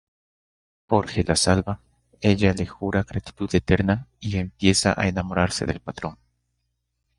/ɡɾatiˈtud/